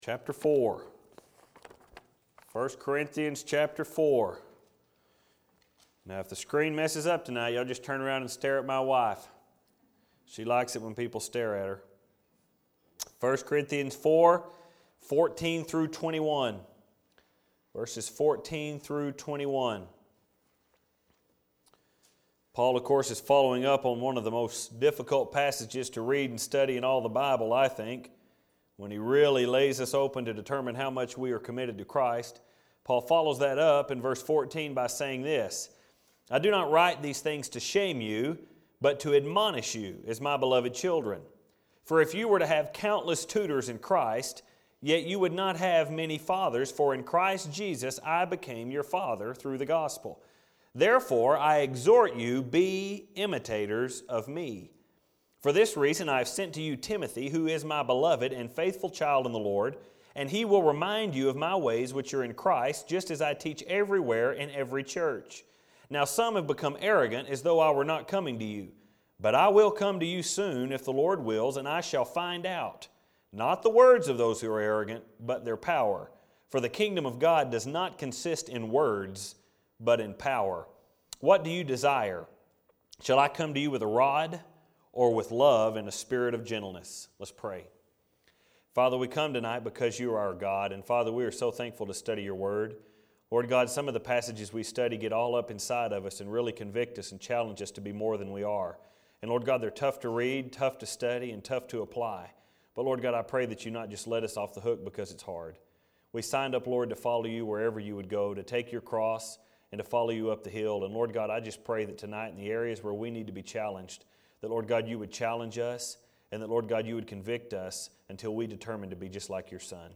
1st Corinthians The Relationship Between Fathers and Sons - First Baptist Church Spur, Texas
Filed Under: Sermons Tagged With: Corinthians